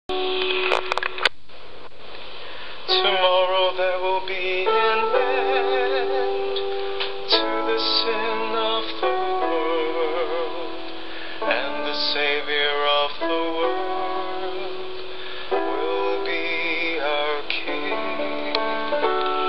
8 PM Christmas Eve Mass, 24 December
Gospel Acc